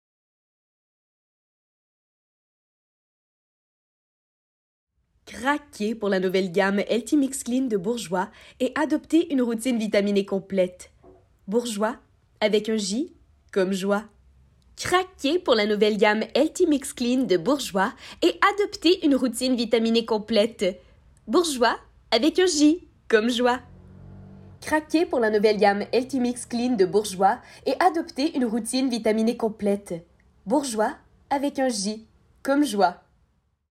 Voix off
18 - 28 ans - Mezzo-soprano